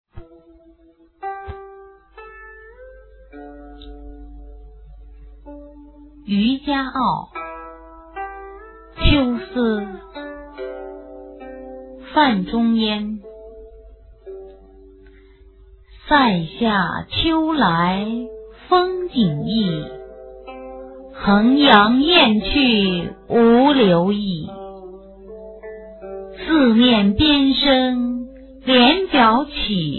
九年级语文下册 12《渔家傲·秋思》女声配乐朗诵（音频素材）